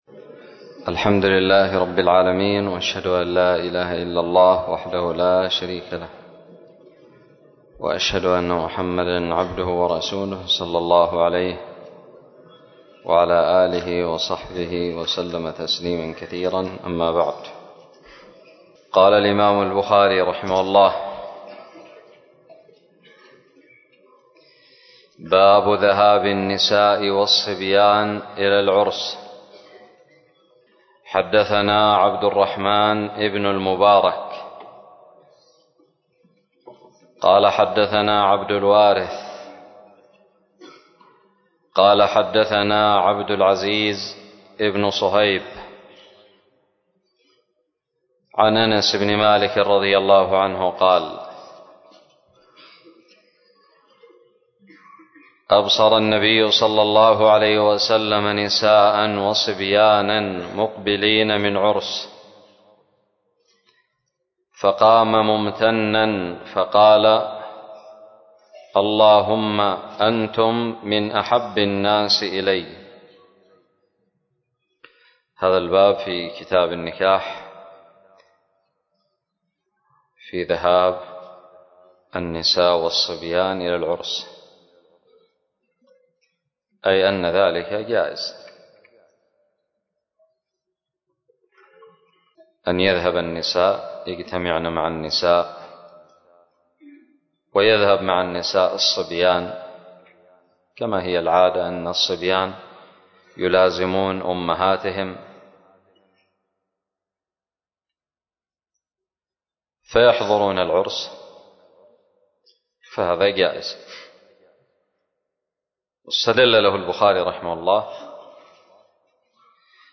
شرح صحيح الإمام البخاري- متجدد
ألقيت بدار الحديث السلفية للعلوم الشرعية بالضالع